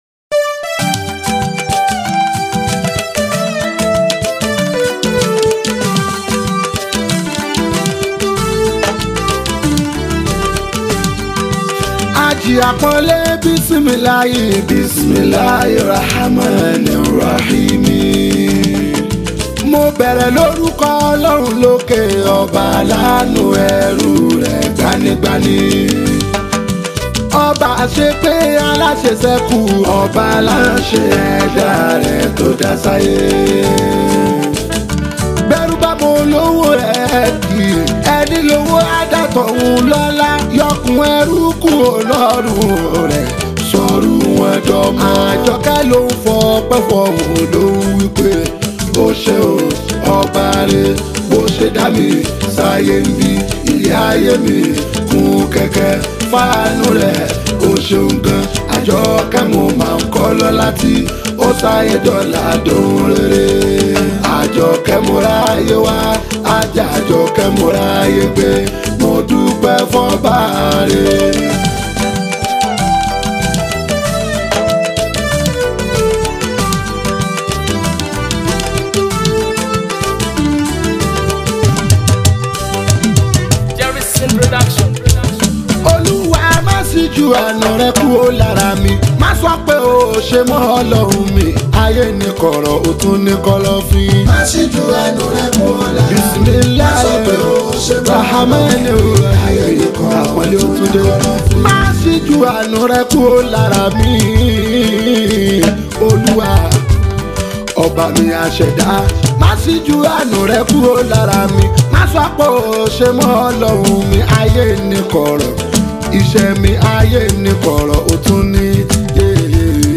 especially people with so much love for Yoruba Fuji Music.